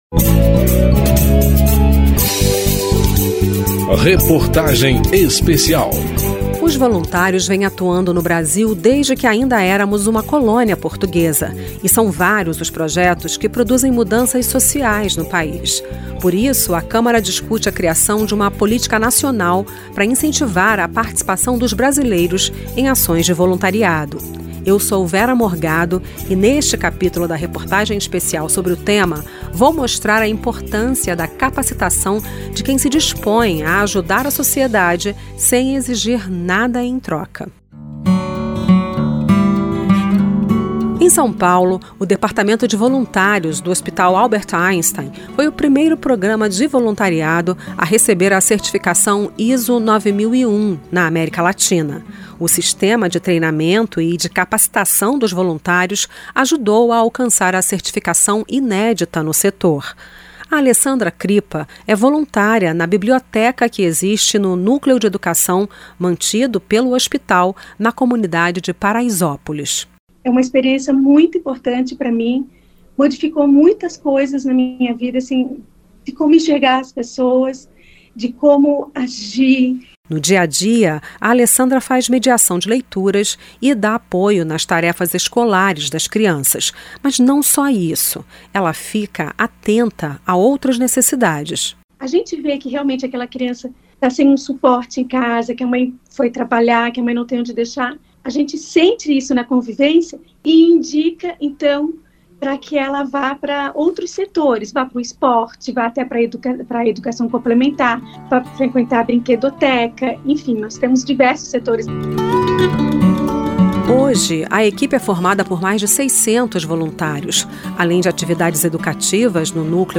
pgm-reportagem-especial-voluntrios-cap-03.mp3